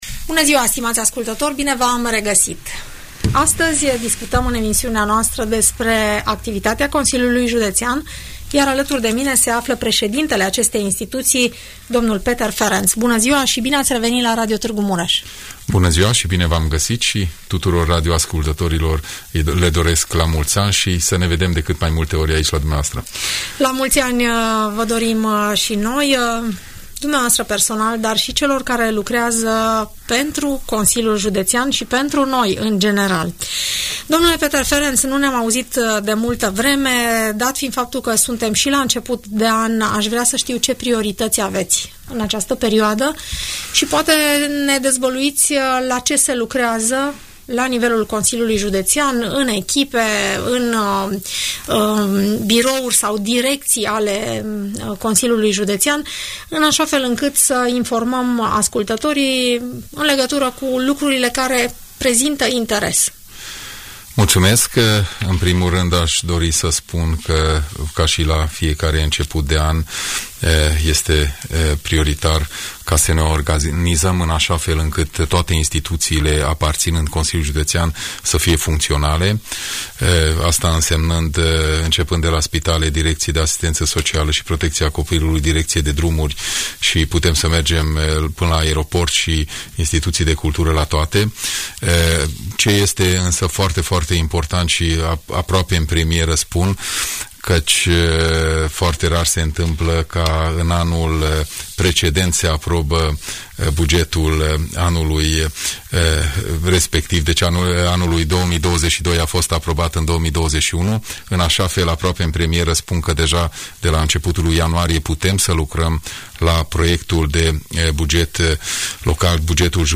Președintele Consiliului Județean Mureș,dl Peter Ferenc, vorbește în emisiunea „Părerea ta”,